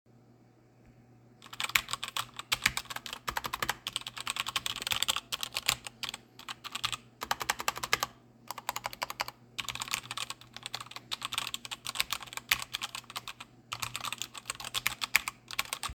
Le Vigor GK41 est équipé de switches mécaniques « Kailh Red », appréciés pour leur réponse linéaire, ils sont assez silencieux.
Au niveau du bruit des touches, les « cliquetis » ne sont pas gênants et on a vite fait de les oublier.
– Bruit peu gênant
MSIsonclavier.mp3